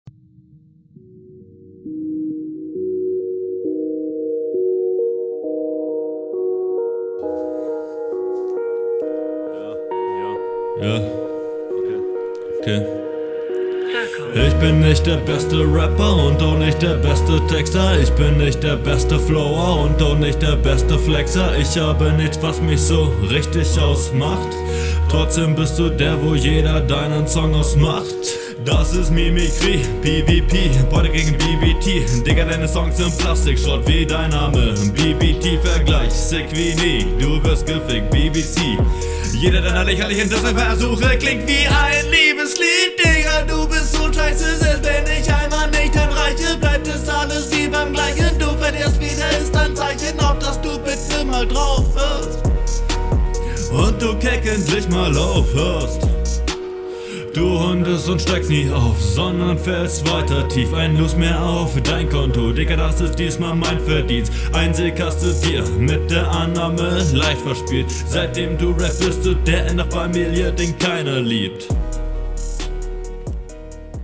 Hinrunde 1
was war das in der Mitte? Hast du reverse stimmbruch gehabt?